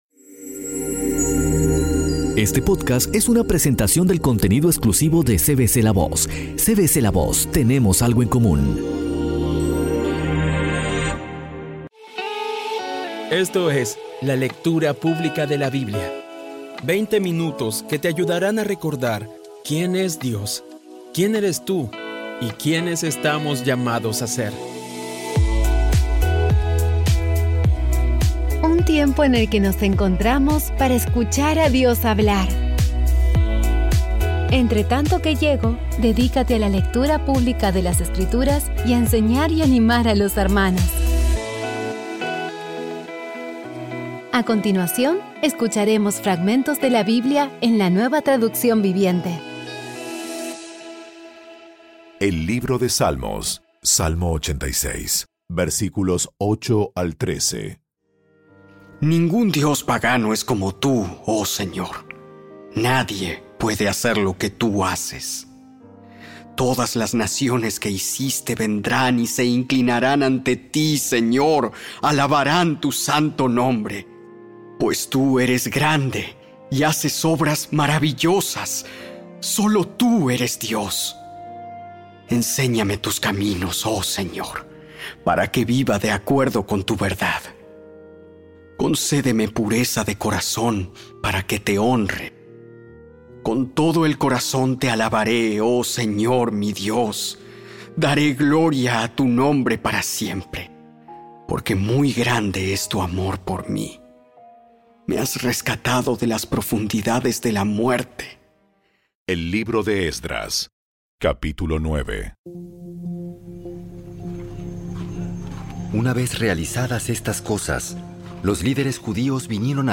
Audio Biblia Dramatizada Episodio 208
Poco a poco y con las maravillosas voces actuadas de los protagonistas vas degustando las palabras de esa guía que Dios nos dio.